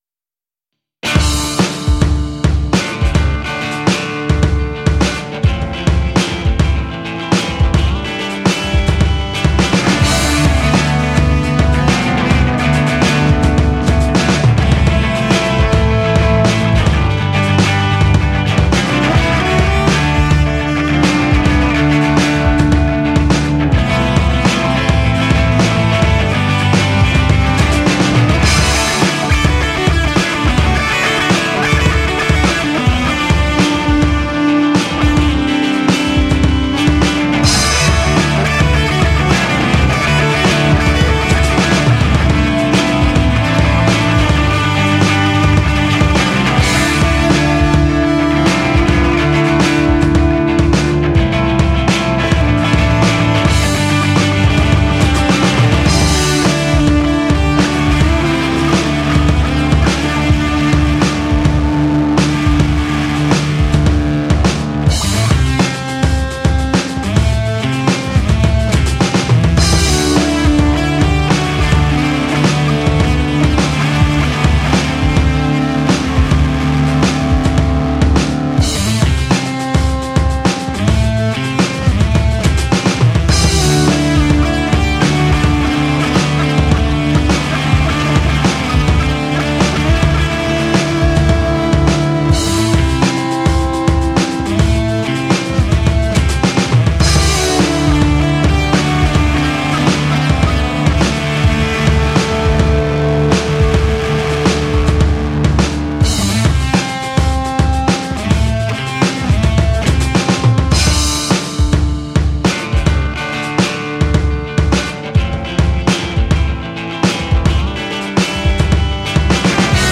An instrumental album.